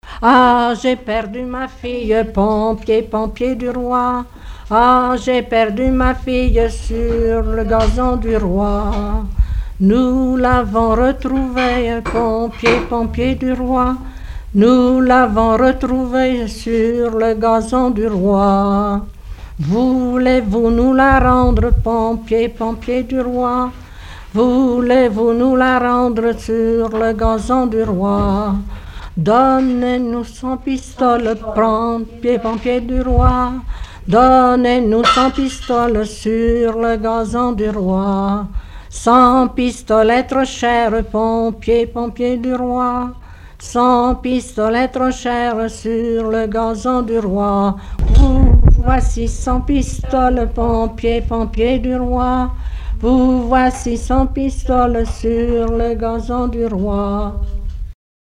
rondes enfantines
Chansons traditionnelles et populaires
Pièce musicale inédite